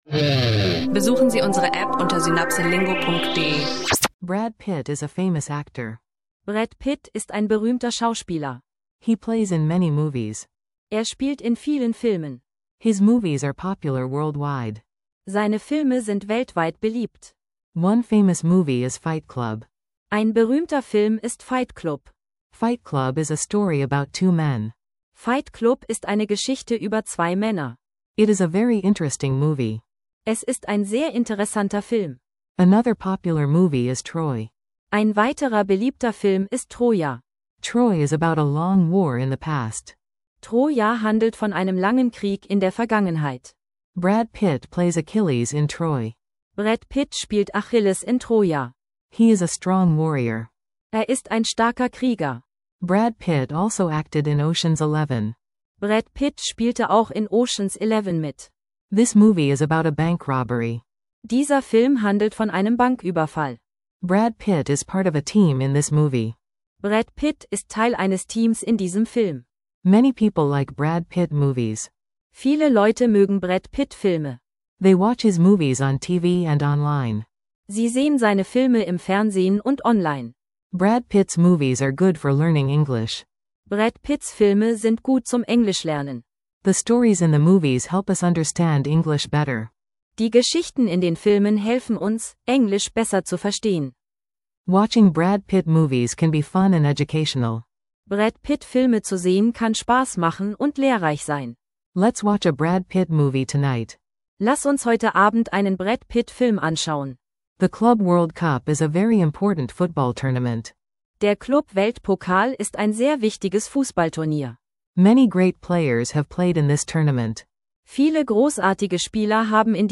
In dieser Folge lernst du Englisch durch spannende Geschichten aus Brad Pitts bekanntesten Filmen. Ein interaktiver Englisch Audio Sprachkurs, perfekt für Anfänger und alle, die Englisch lernen unterwegs möchten.